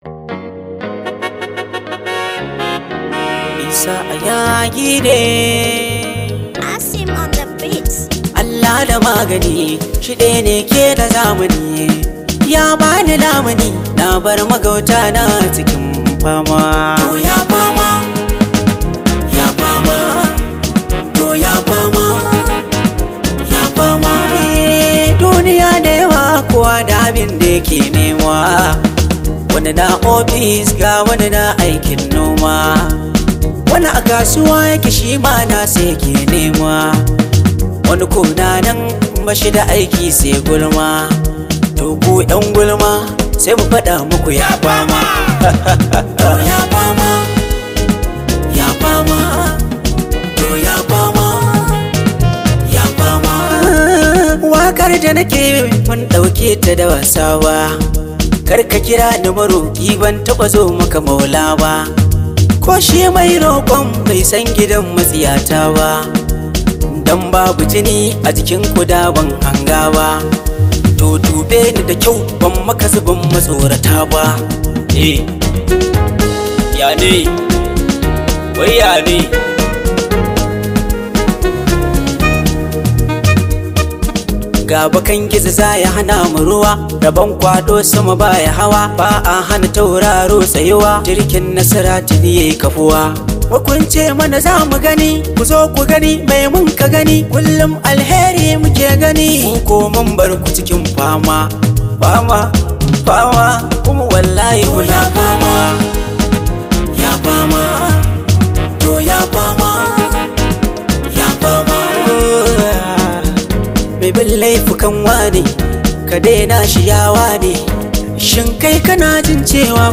Wakokin Hausa
incorporating traditional Nigerian elements